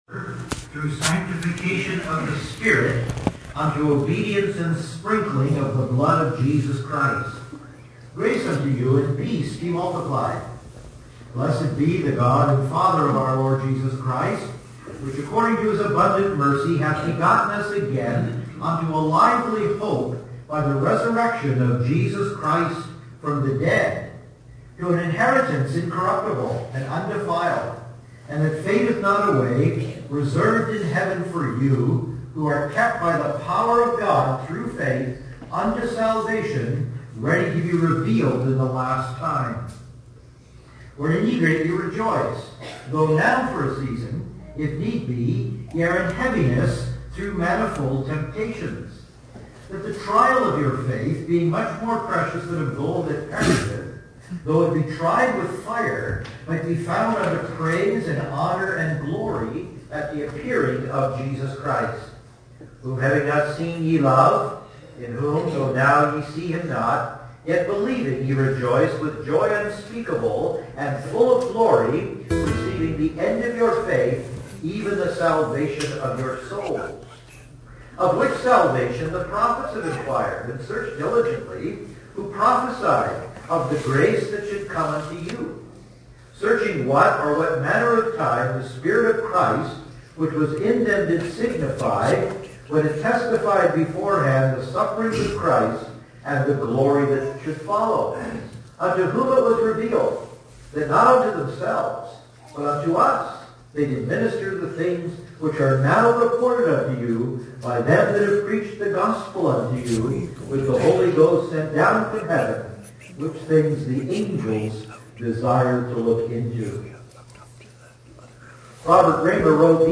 We apologize for the sound quality of this message.